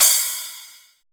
LITE SPLASH.wav